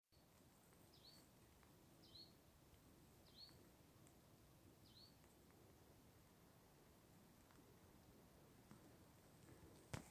московка, Periparus ater
СтатусСлышен голос, крики